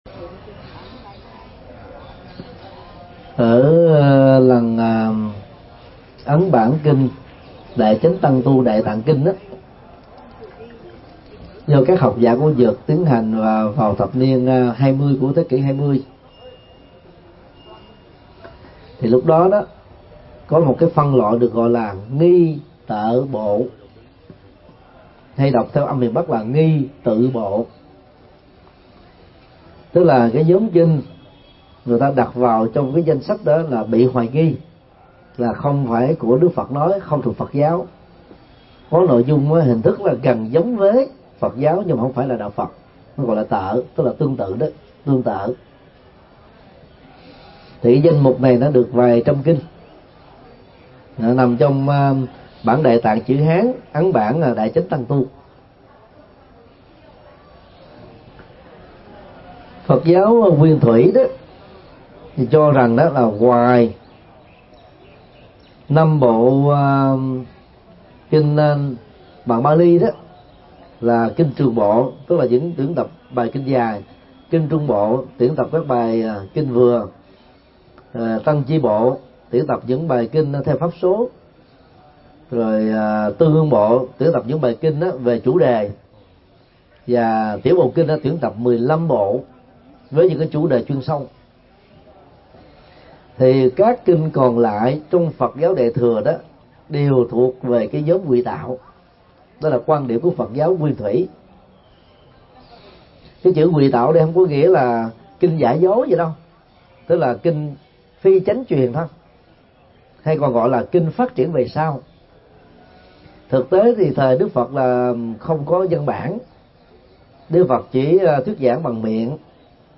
Vấn đáp: Phân biệt Ngụy Kinh và Chân Kinh – Thượng Tọa Thích Nhật Từ